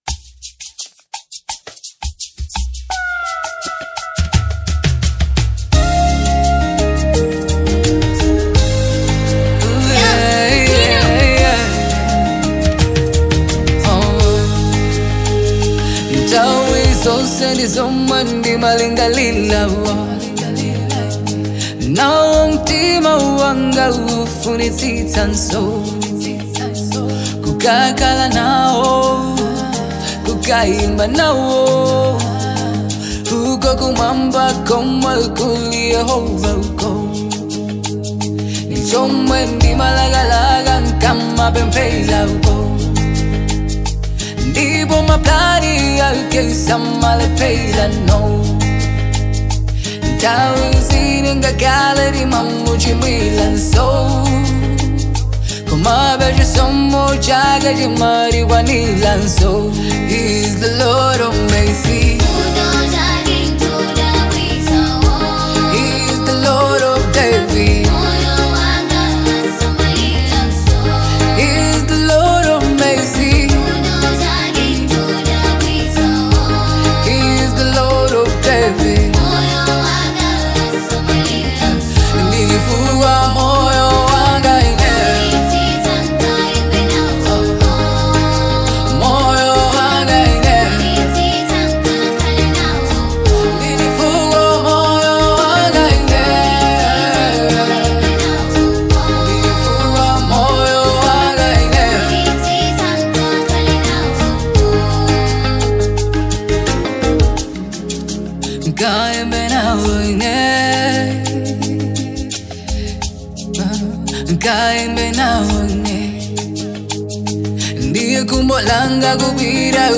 Afro-Gospel